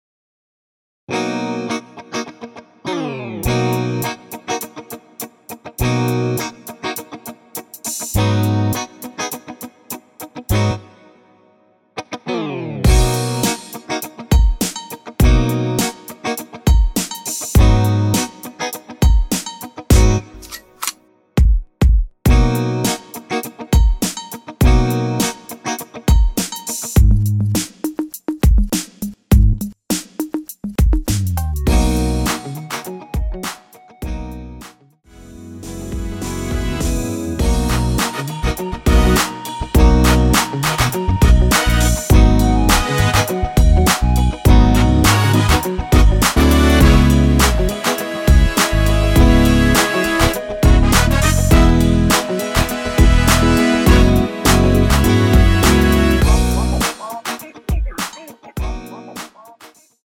전주 없이 시작 하는 곡이라서 1마디 전주 만들어 놓았습니다.(미리듣기 확인)
3초쯤 노래 시작 됩니다.
원키에서(-2)내린 멜로디 포함된 MR입니다.(미리듣기 확인)
앞부분30초, 뒷부분30초씩 편집해서 올려 드리고 있습니다.